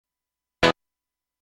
UM-880経由の方は波形通り、各テイクの出音に差はないんですが、MIDIデータに忠実かどうかは疑問です。
素材は、ギターの “Ｅコード” を鳴らしてるんですが、低音側と高音側とでは、音の立ち上がりが違うために、